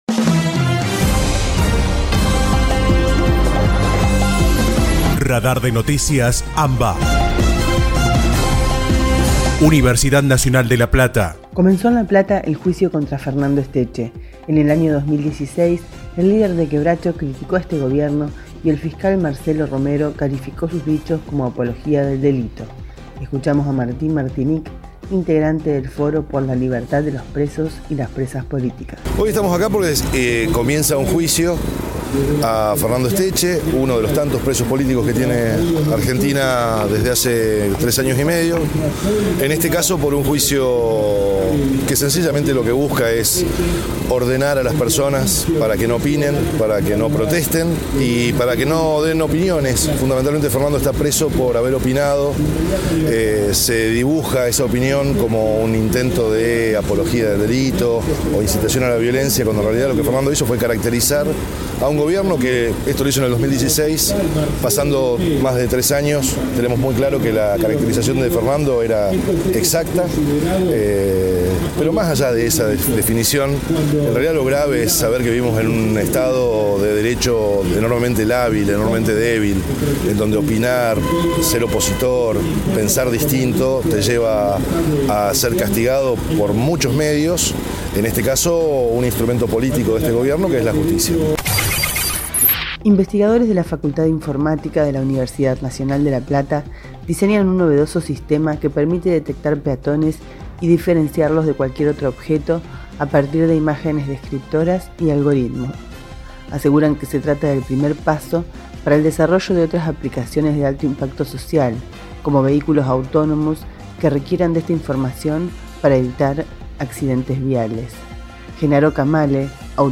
Panorama informativo «Radar de Noticias AMBA» , realizado de manera colaborativa entre las emisoras de las Universidades Nacionales de La Plata, Luján, Lanús, Arturo Jauretche, Avellaneda, Quilmes, La Matanza y General Sarmiento, integrantes de ARUNA (Asociación de Radiodifusoras Universitarias Nacionales Argentinas).